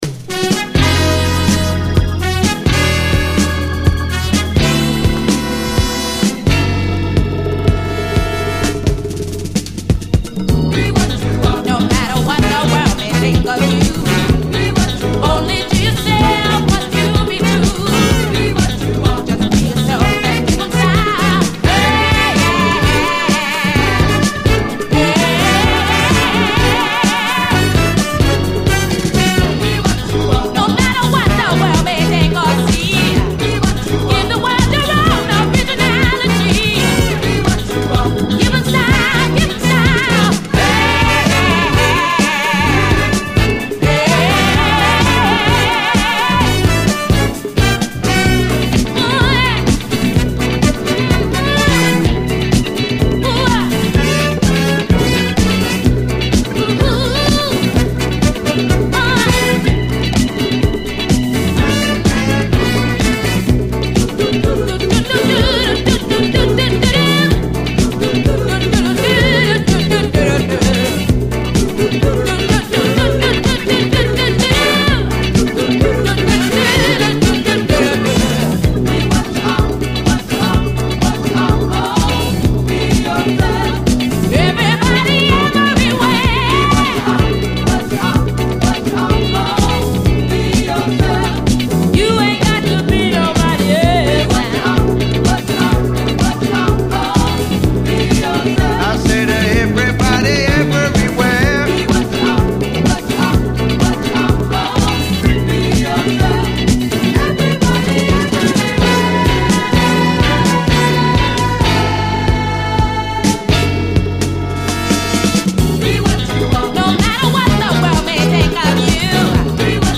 ブラック・サントラ〜レアグルーヴ名盤！